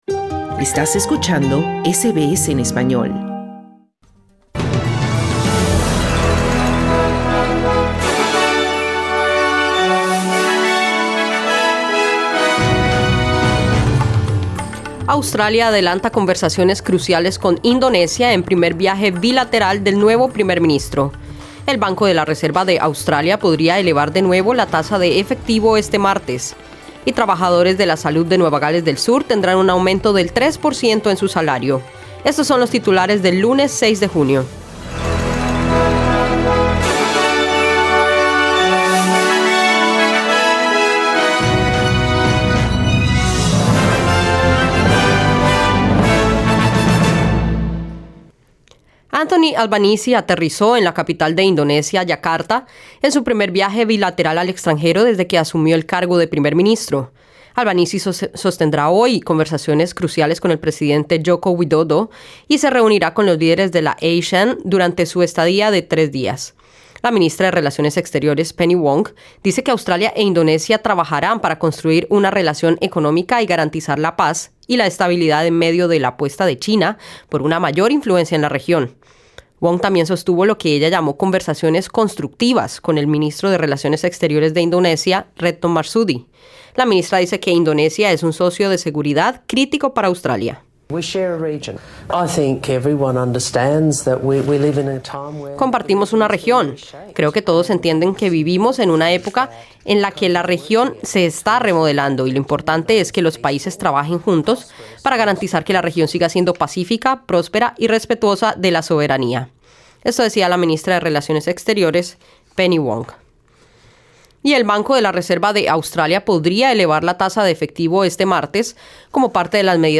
Noticias SBS Spanish | 6 junio 2022